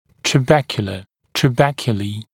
[trəˈbekjulə] мн. [trəˈbekjuliː][трэˈбэкйулэ] мн.